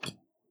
BottleFoley2.wav